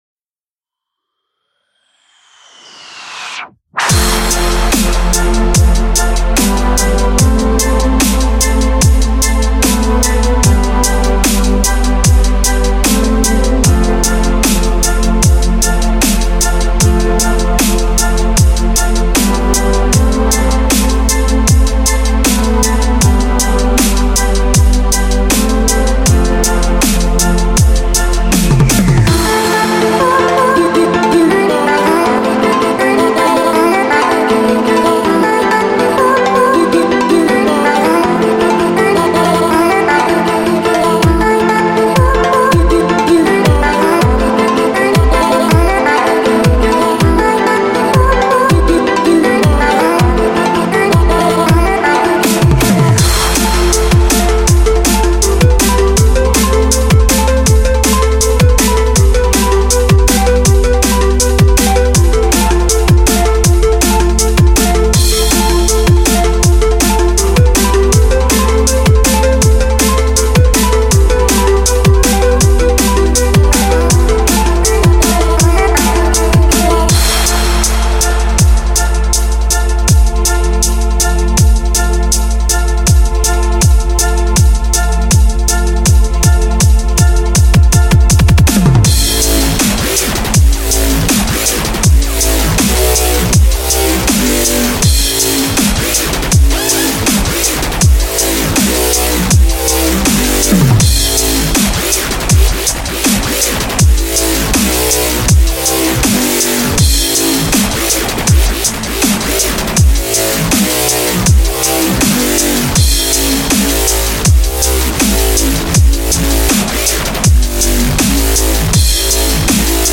This is a terrible remix.
The only good part was the breakdown.